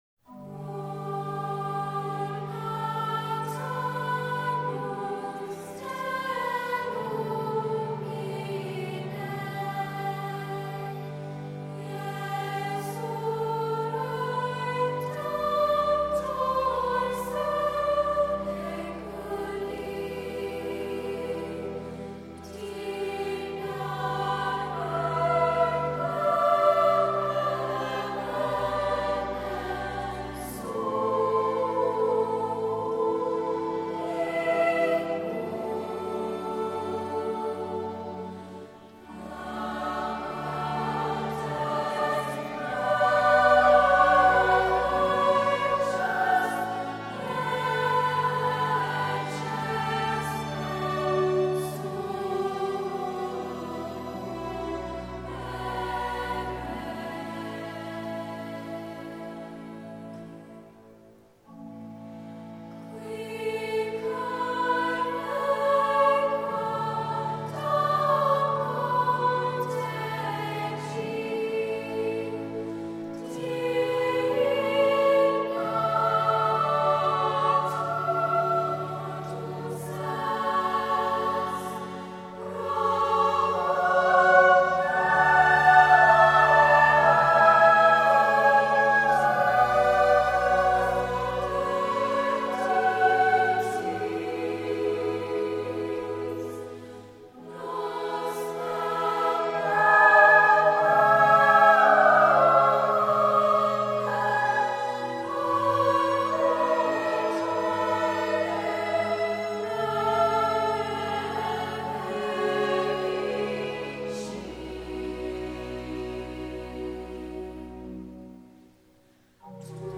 • Voicing: ssa
• Accompaniment: organ